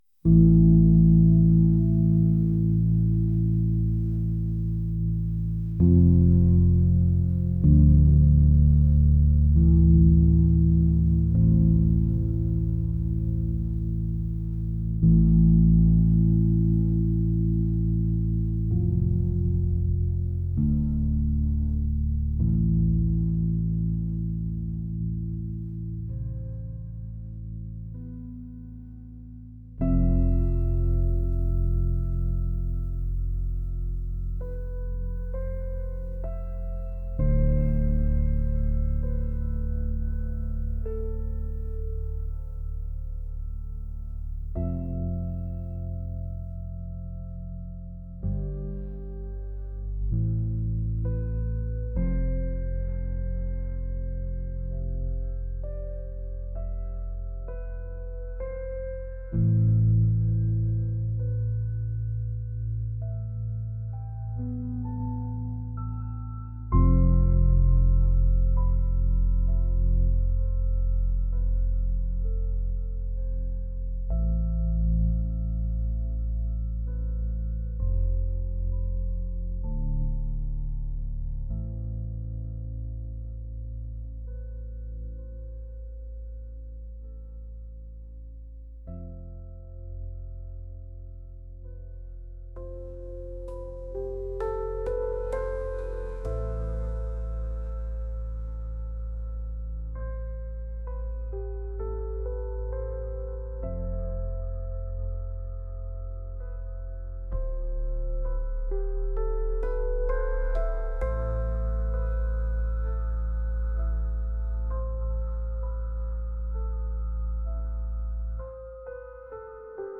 atmospheric